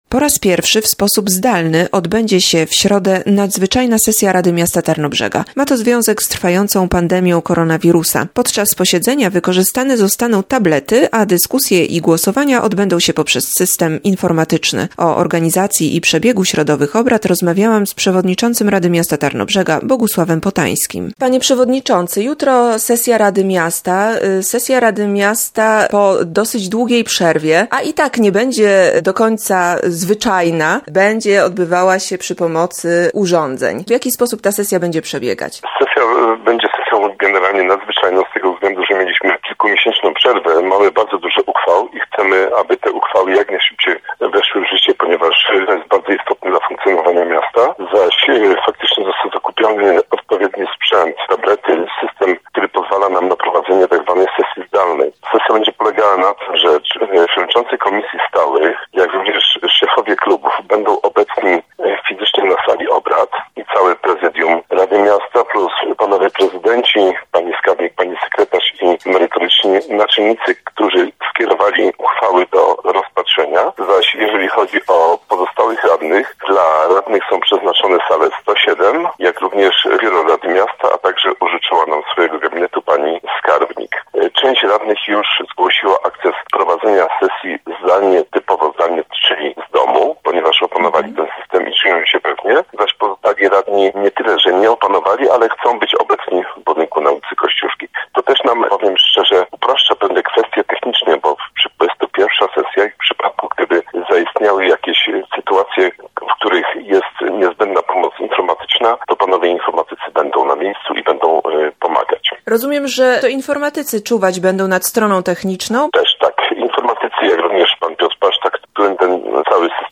Rozmowa z przewodniczącym Rady Miasta Tarnobrzega, Bogusławem Potańskim.